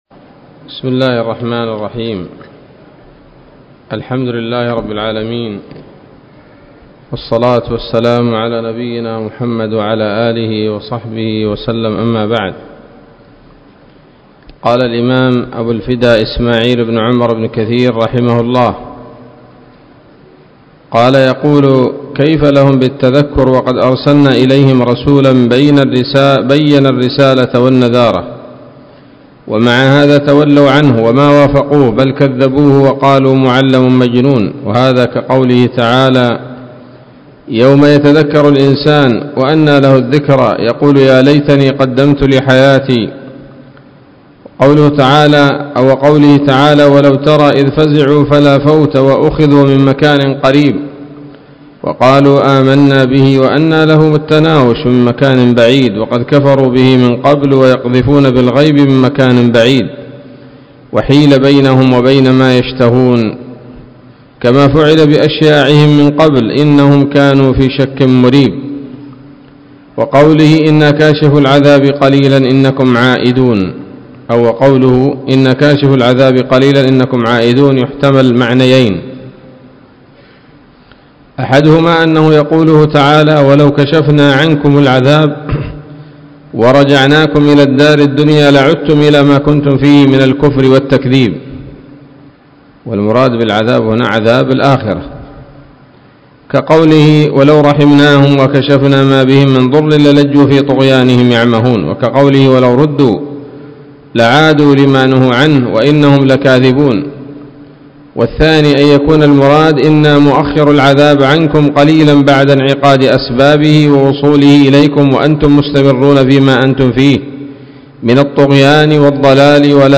الدرس الثالث من سورة الدخان من تفسير ابن كثير رحمه الله تعالى